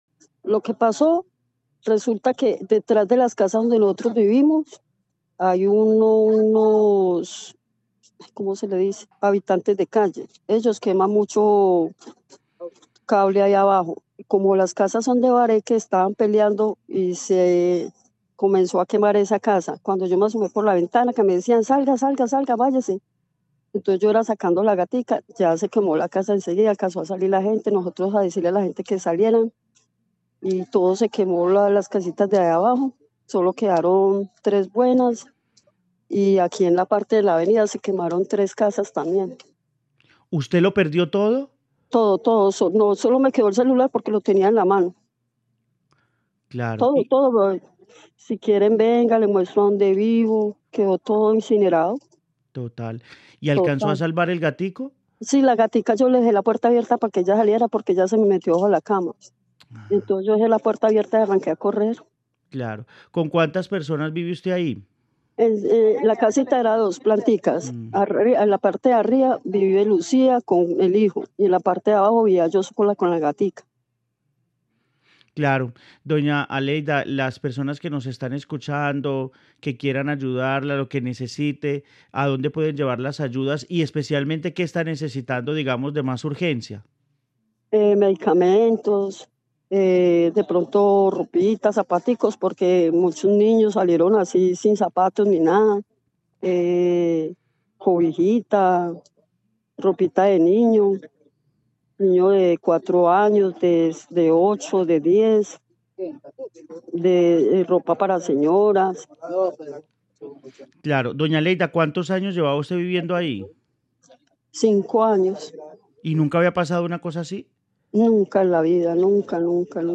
damnificada de incendio